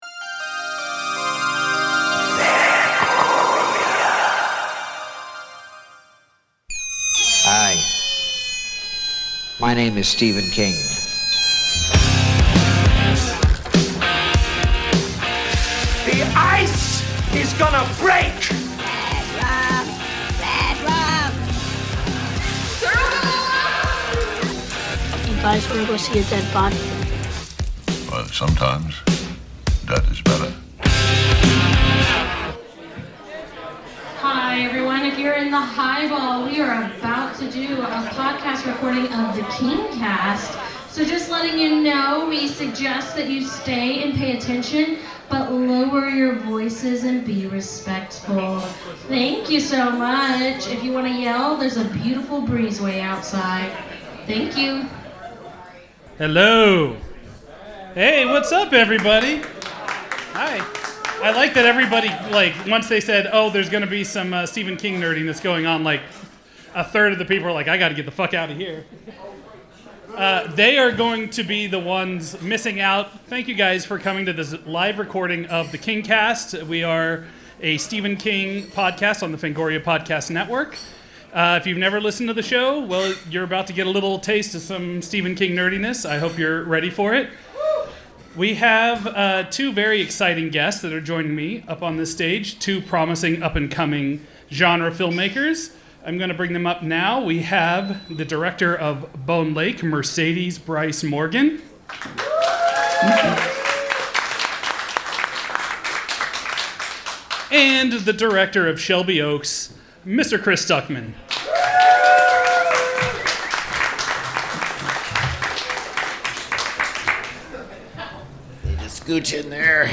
This Kingcast episode was recorded in front of a live (rowdy bar) audience.